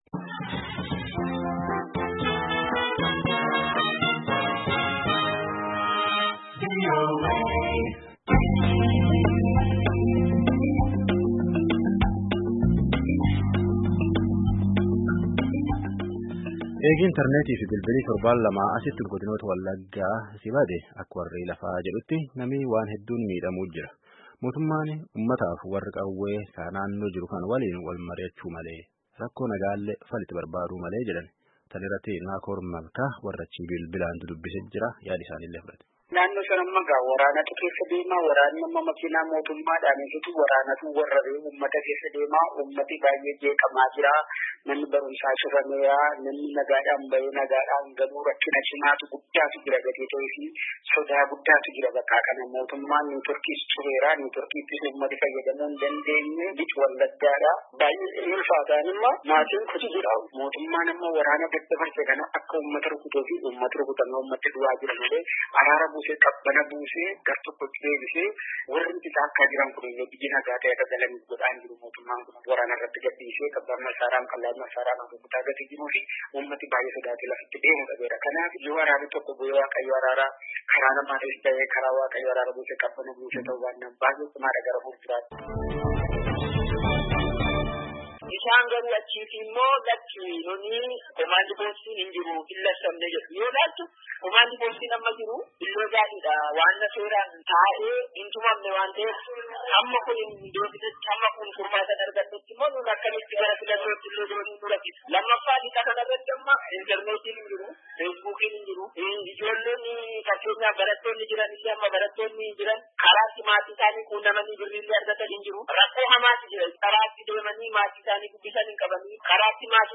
Yaada namootaa walitti-qabne dhaggeeffadhaa